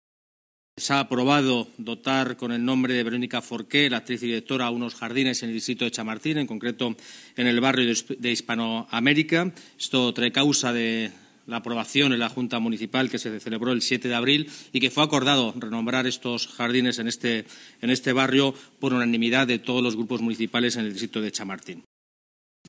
Nueva ventana:habla el delegado de Medio Ambiente y Movilidad, Borja Carabante